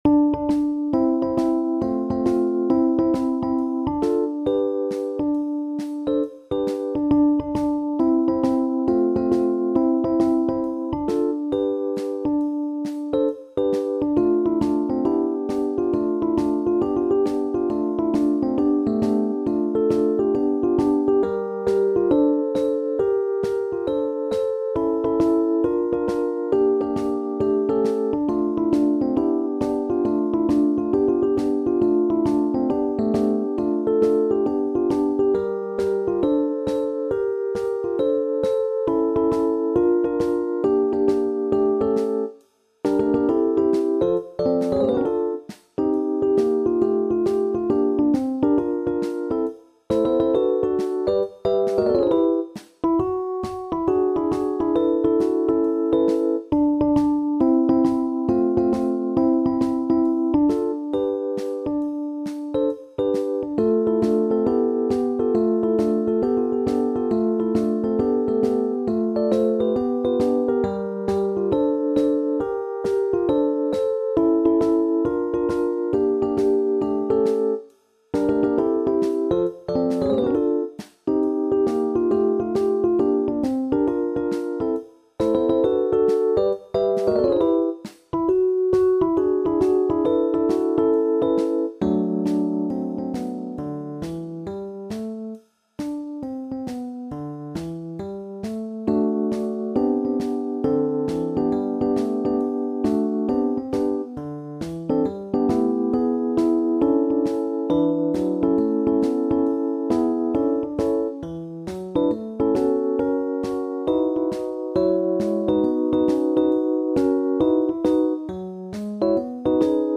SSAA
Meidenpop met Motown-geluid